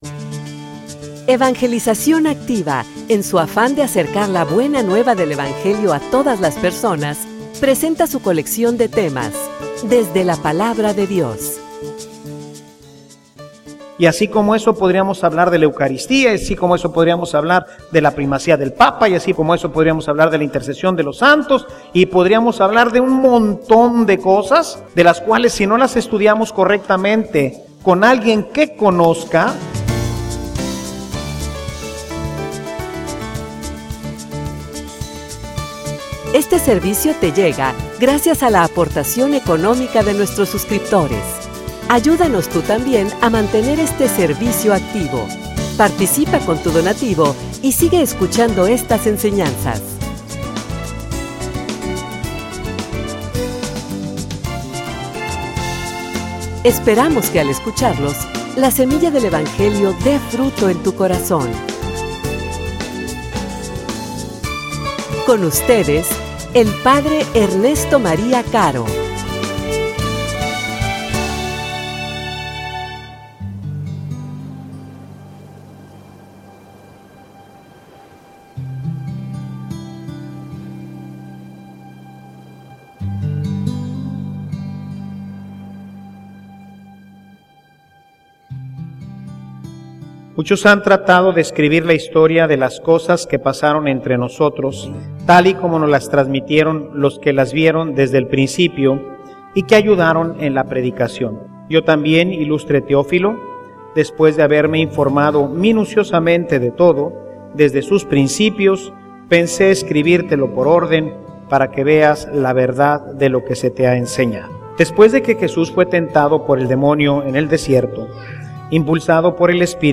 homilia_Solidifica_tu_fe.mp3